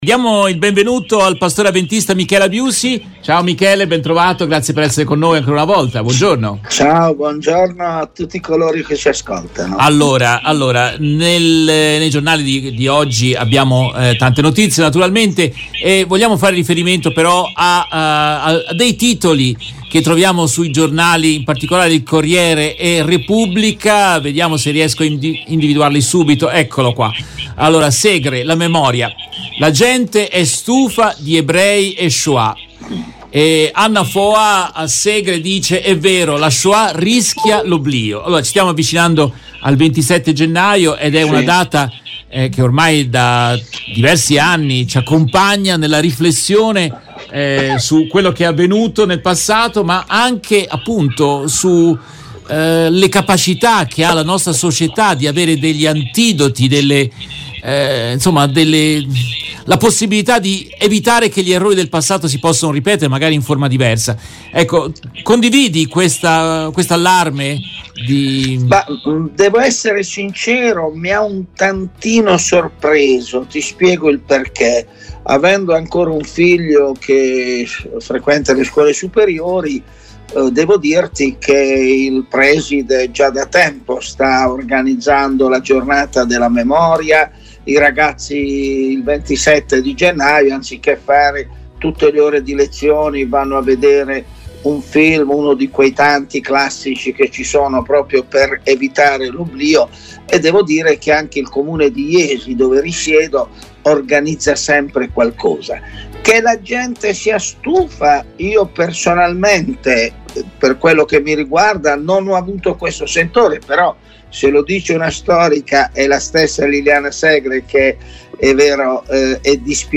In questa intervista tratta dalla diretta RVS del 24 gennaio 2023